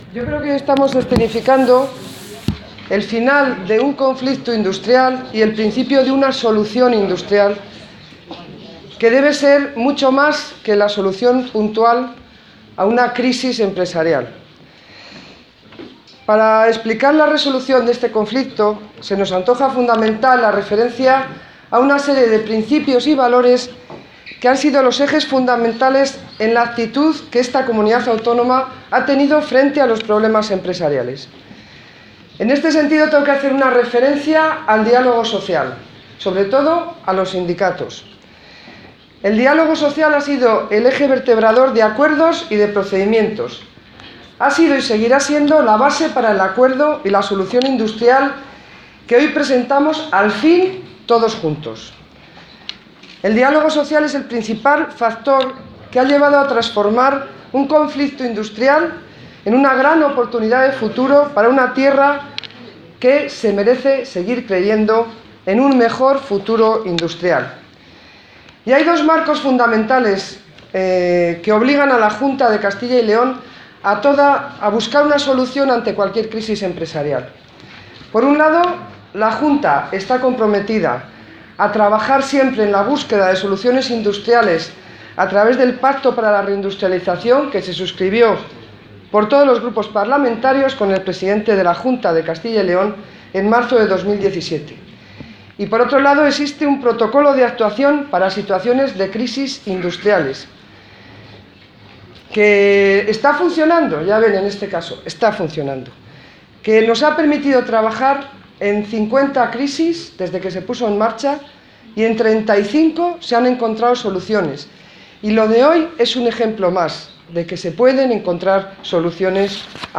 Consejera de Economía y Hacienda.